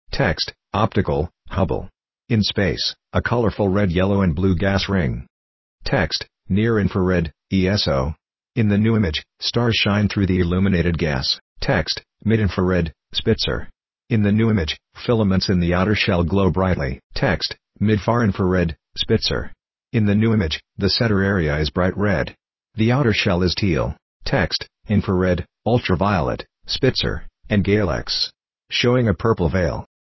• Audio Description